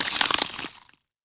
autocannon_grenade_pickup2.wav